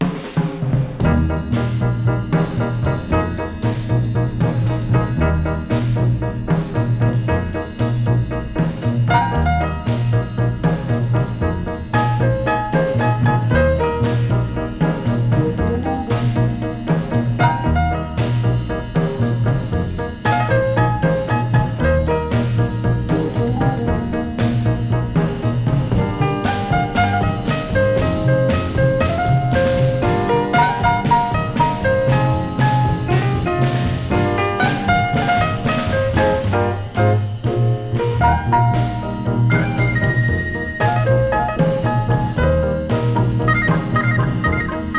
グルーヴィーでヒップな演奏、小編成で色彩豊かなアレンジ、遊び心満載のエキサイティングな好盤。
Jazz, Soul-Jazz, Easy Listening　USA　12inchレコード　33rpm　Stereo